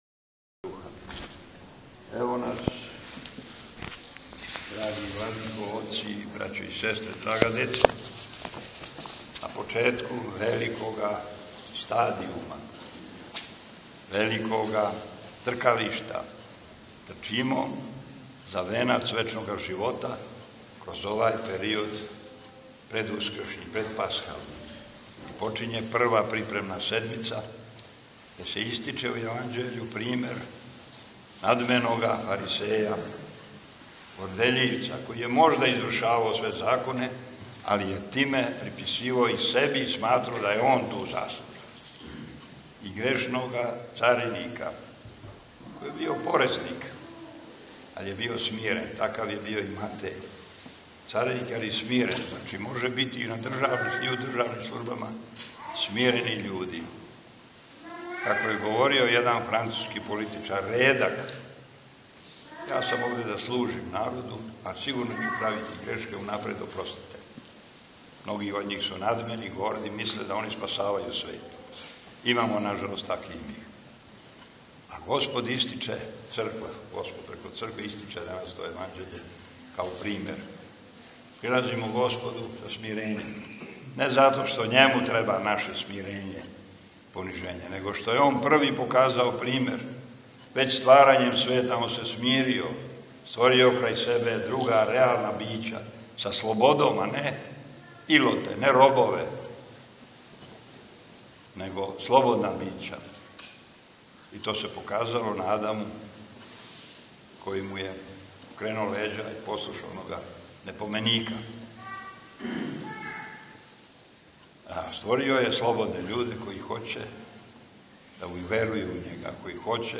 Након прочитанога Јеванђеља Епископ Атанасије се присутнима обратио беседом.